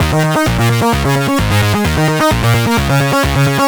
Power Bass Eb 130.wav